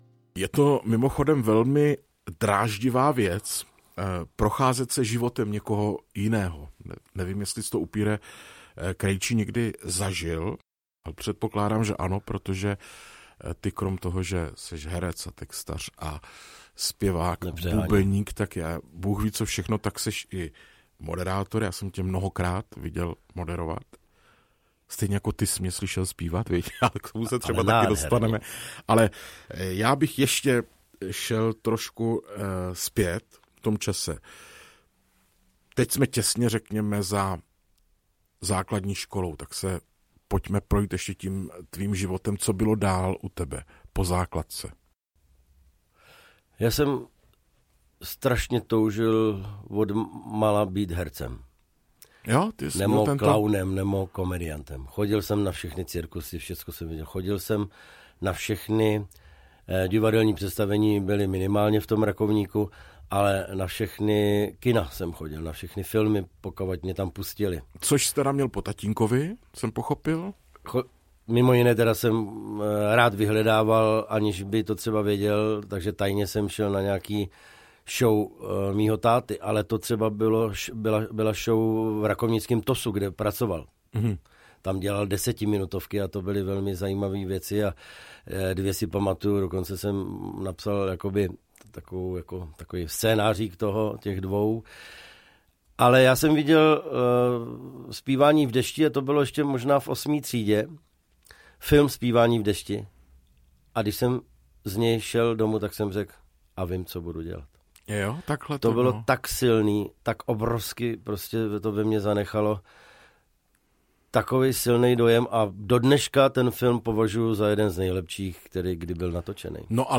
Galerie slávy - Václav Upír Krejčí audiokniha
Ukázka z knihy
Každý díl GALERIE SLÁVY je de facto mluveným životopisem konkrétní osobnosti.